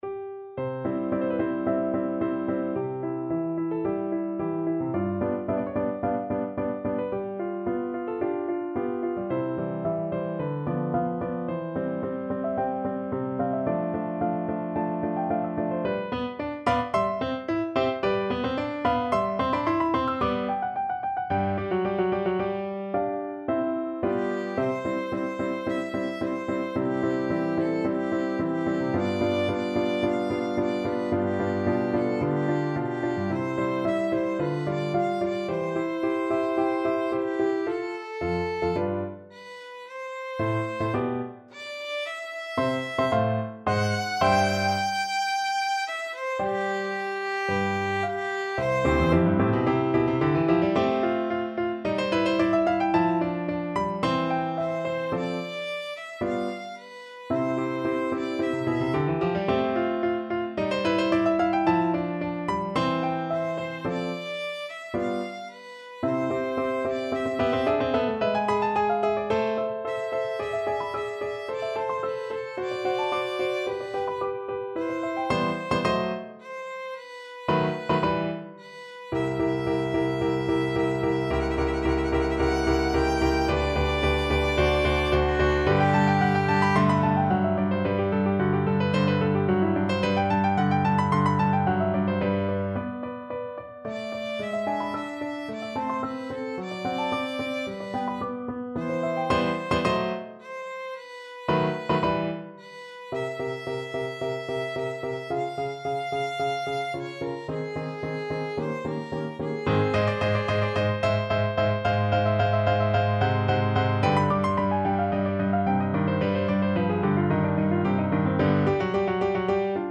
Violin
C major (Sounding Pitch) (View more C major Music for Violin )
Andante =110
4/4 (View more 4/4 Music)
Classical (View more Classical Violin Music)